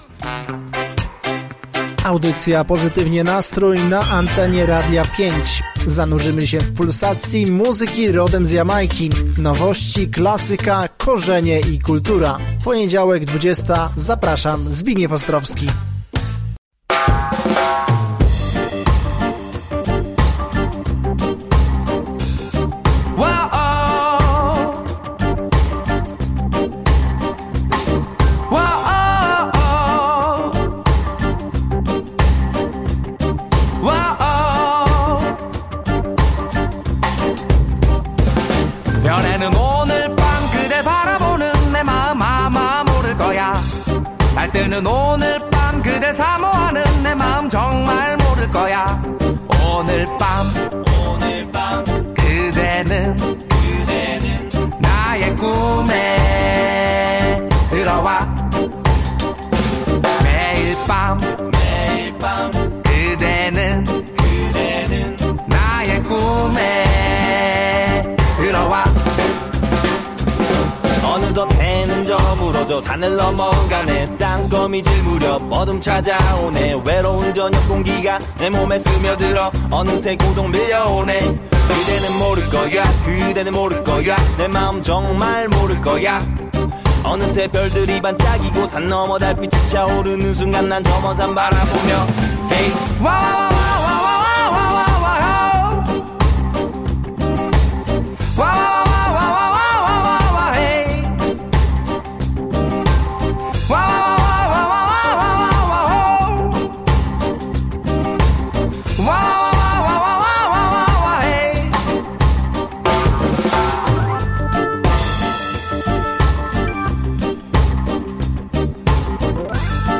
Ostatnia audycja, w której myślami i dźwiękami wracamy do Ostródy.